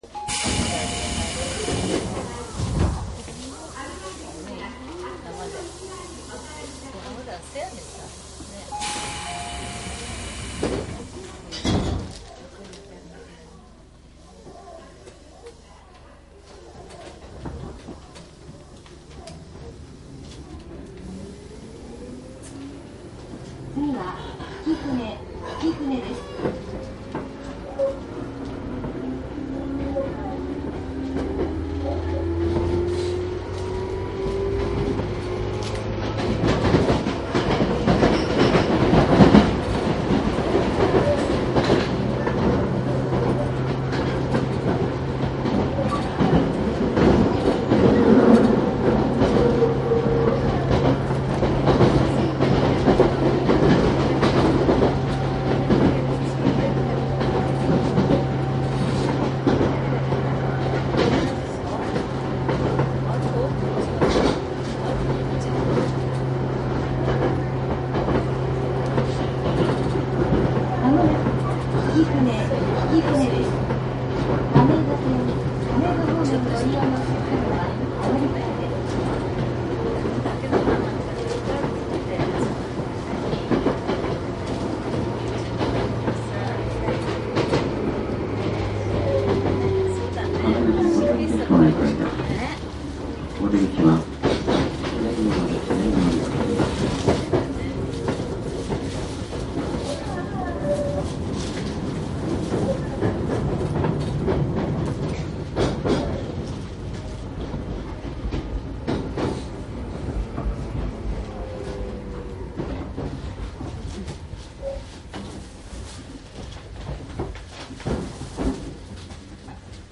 東武鉄道１００５０系 東武伊勢崎線収録
■ 東武１００５０系 （モハ１1２５５・界磁チョッパ）
東武伊勢崎線 （準急）浅草→東武動物公園 ＜収録日：1999-10-17（日）＞
注意事項.収録機材は、ソニーDATと収録マイクソニーECM959を使用.。